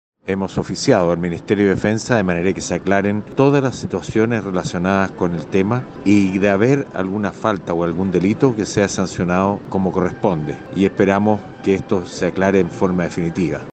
El diputado de Renovación Nacional y miembro de la misma comisión, dijo que ya se ha oficiado al Ministerio de Defensa para que se esclarezcan todos los antecedentes.